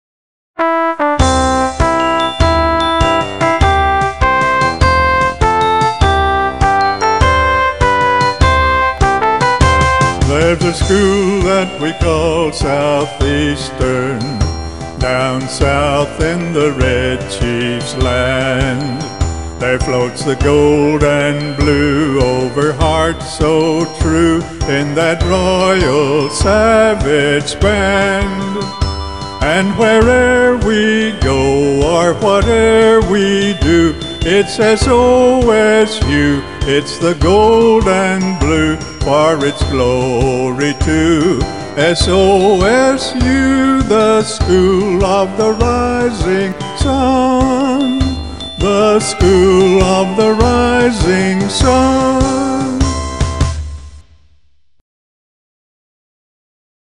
vocals on all songs, except: